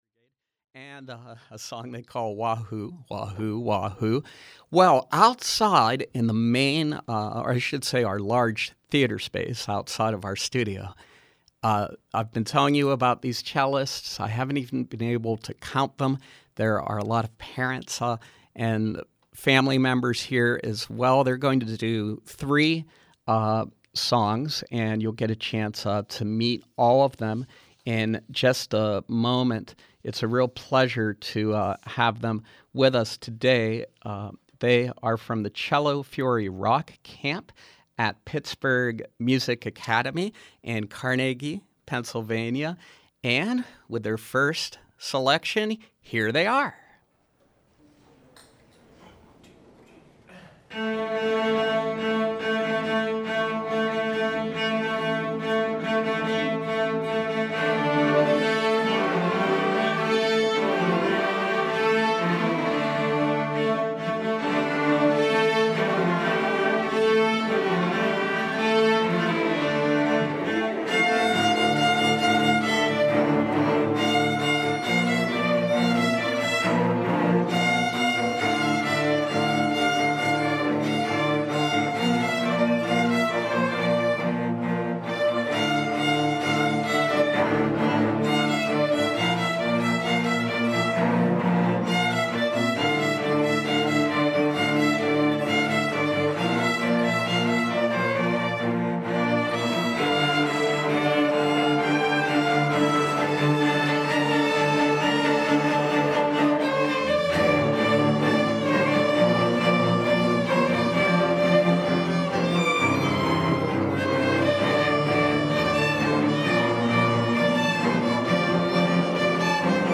From 6/29/13: Cellists from the Cello Fury Rock Camp at Pittsburgh Music Academy in Carnegie